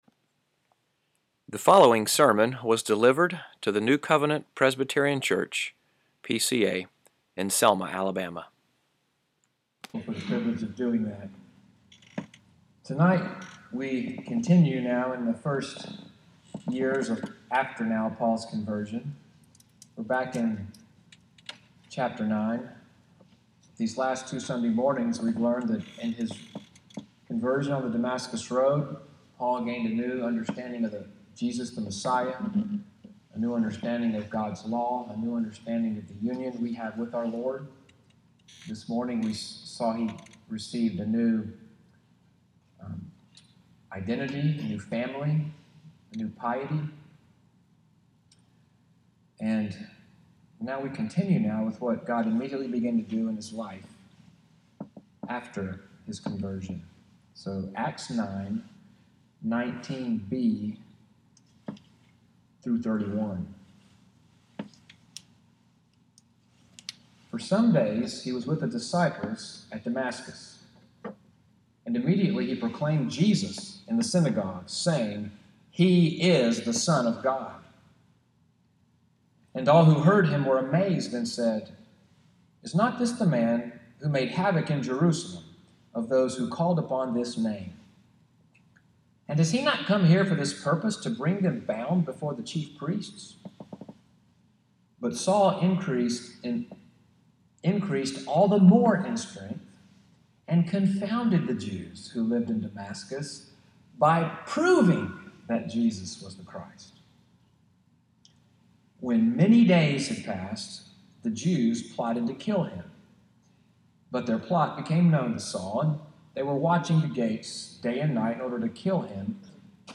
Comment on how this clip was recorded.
EVENING WORSHIP at NCPC, sermon audio “Brother Saul is Here“, July 16, 2017